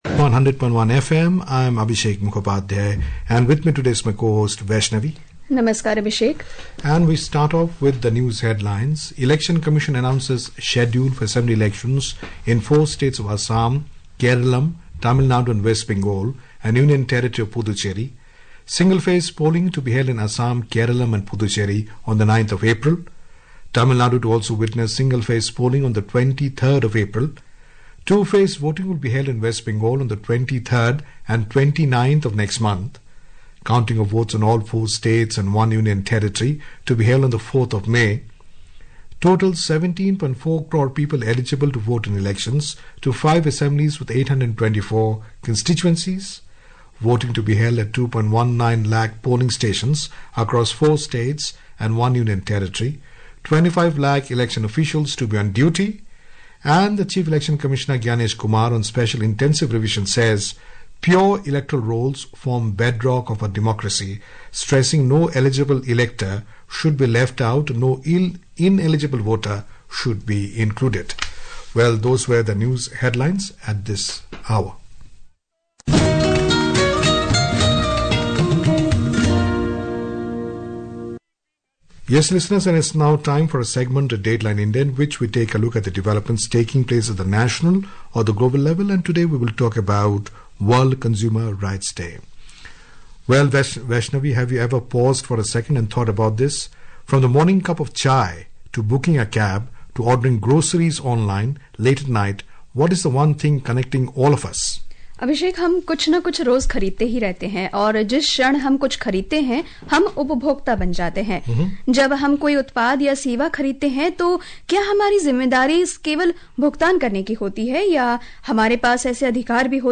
This audio news bulletin titled Hourly News in the category Hourly News .